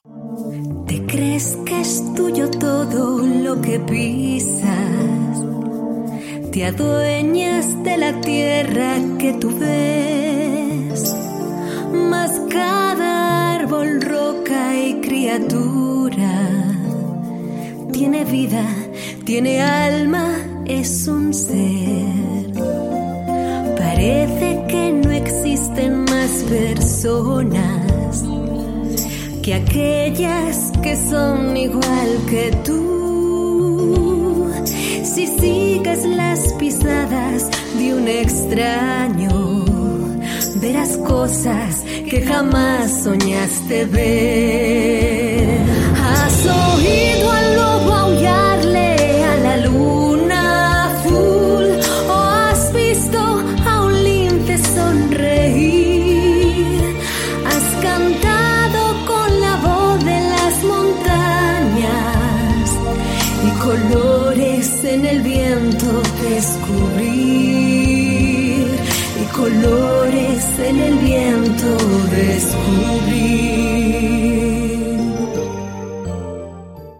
ACTRIZ DOBLAJE / LOCUTORA PUBLICITARIA / CANTANTE Voz versátil, cálida, elegante, sensual, fresca, natural, dulce, enérgica...
kastilisch
Sprechprobe: Sonstiges (Muttersprache):
A versatile, experienced , clear, credible and vibrant voice over artist.